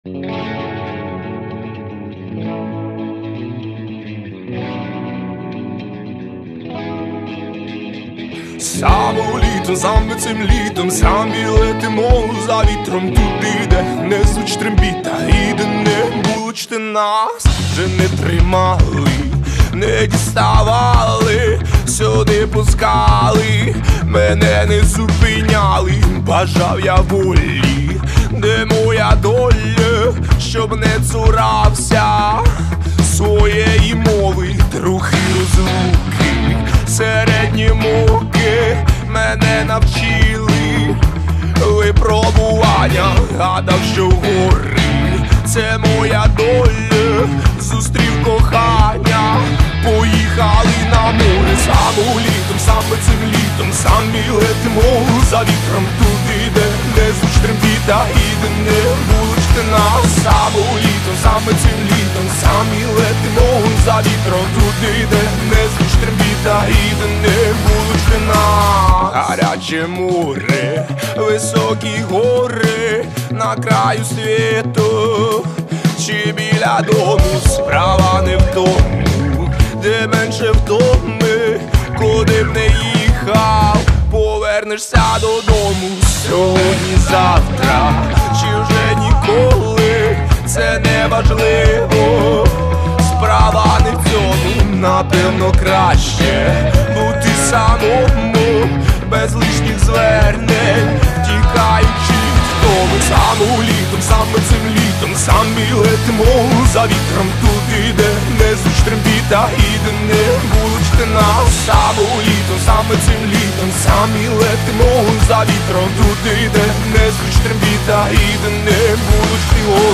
Всі мінусовки жанру Pop-Rock
Плюсовий запис